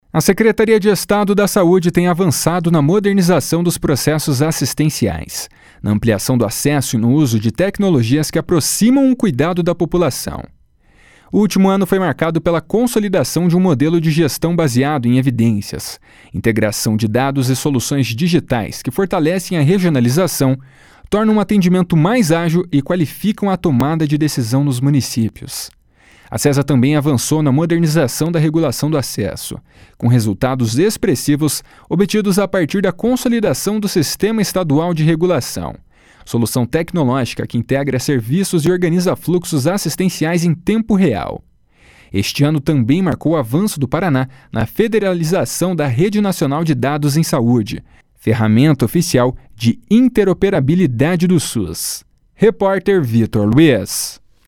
(Repórter: